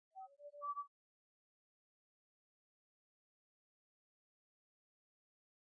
群众的尖叫声，A
描述：对于我的第666次上传，这里有些令人不寒而栗 数百人尖叫。在迪士尼的“探险珠穆朗玛峰”中，将近200张我自己的过山车尖叫声拼凑在一起。我取了结果并将其贴在反面，以增加不适感。由于过山车隆隆声，降低了500Hz以下的频率。很多不幸的蝉杂乱在高端，但应该与其他尖叫的声音很好地融合。
使用“Zoom H1录音机”录制声音。
标签： 恐怖 尖叫 恐怖 恐怖 恐怖 尖叫 尖叫 恐怖 尖叫 人群 人群 可怕
声道立体声